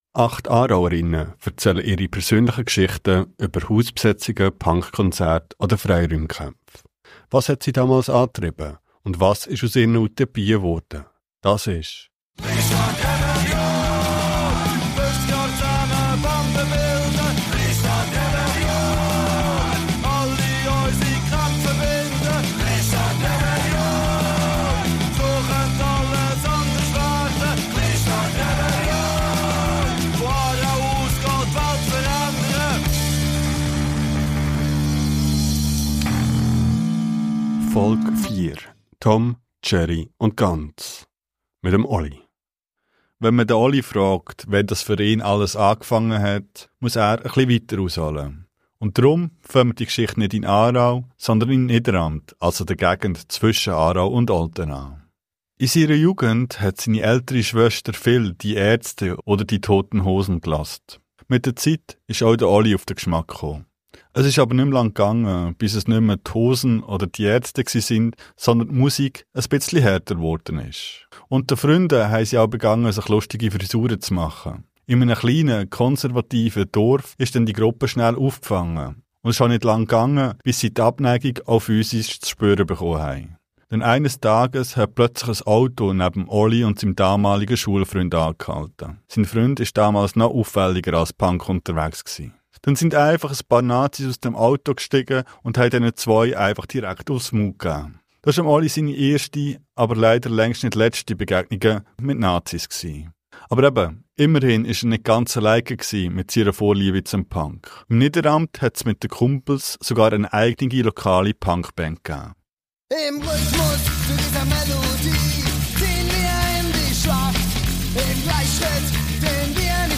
1 Als der Punk nach Schwaben kam - ein Gesprch ber den Schleimkeim-Film